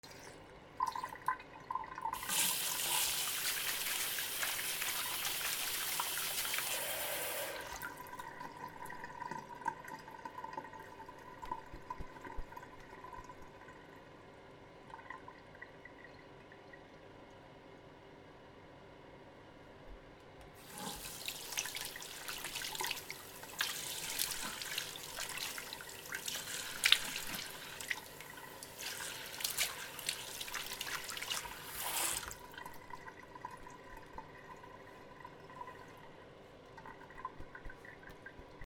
手を洗う 顔を洗う 洗面所